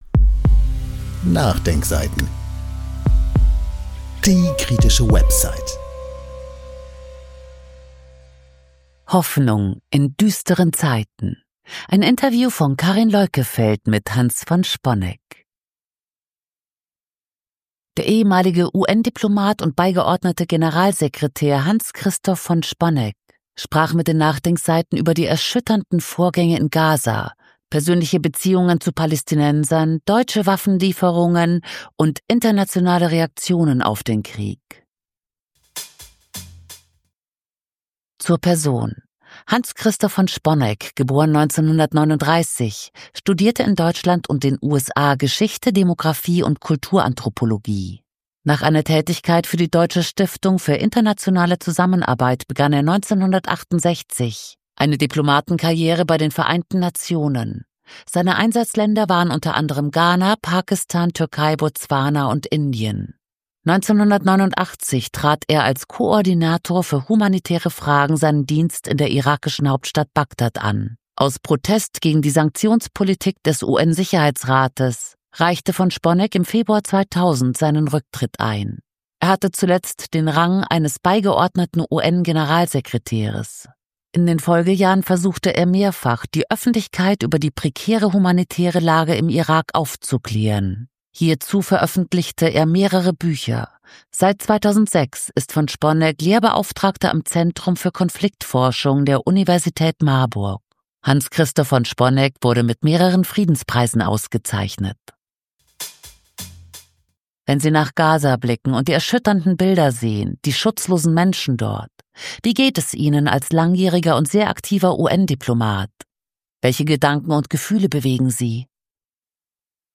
NachDenkSeiten – Die kritische Website > Hoffnung in düsteren Zeiten – Interview mit Hans von Sponeck